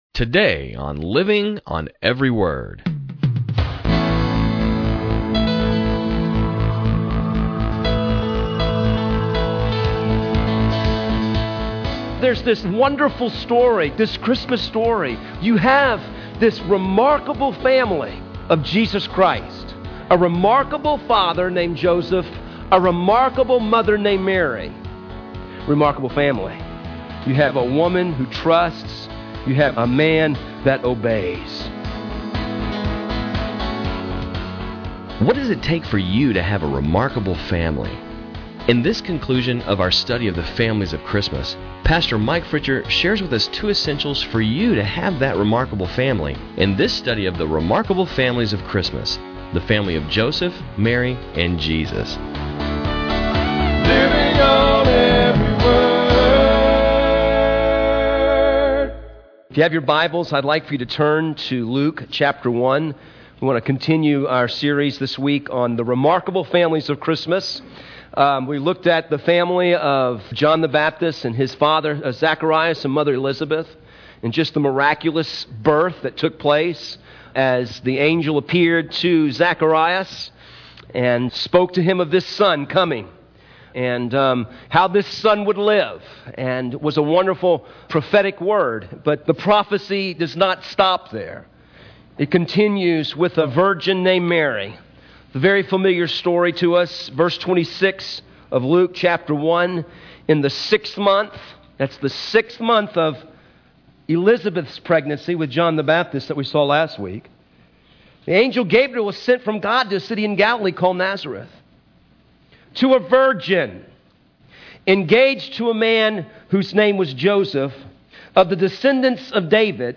sermons on cd